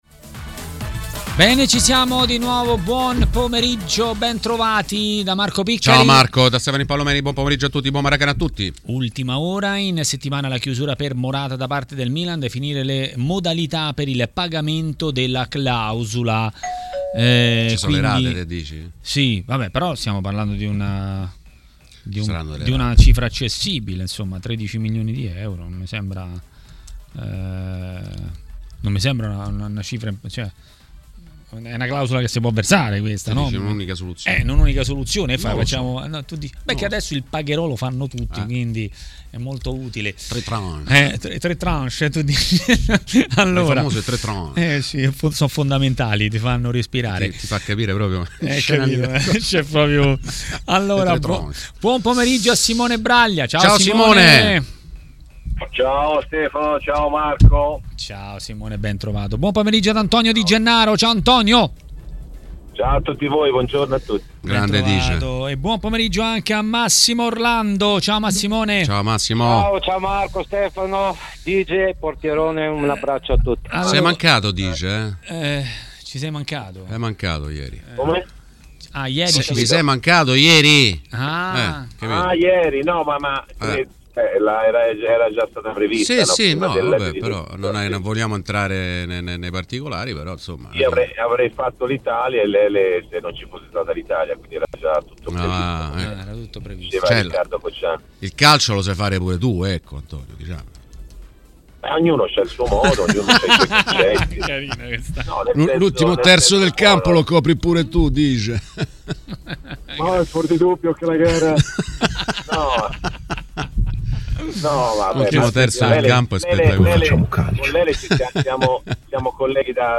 A TMW Radio, durante Maracanà, è stato ospite l'ex calciatore e commentatore tv Antonio Di Gennaro.